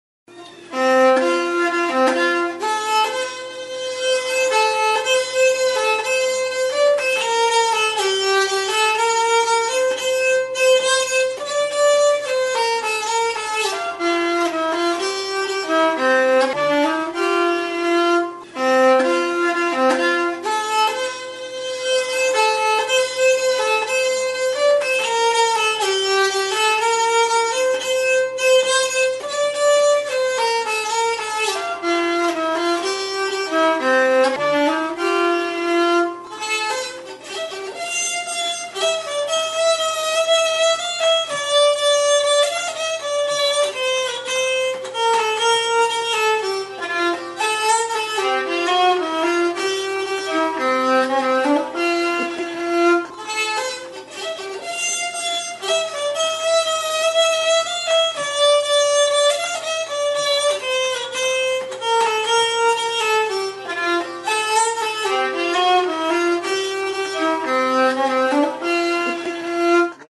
Instruments de musiqueNYCKELHARPA
Cordes -> Frottées
Enregistré avec cet instrument de musique.
Hariak igurzteko arkua du.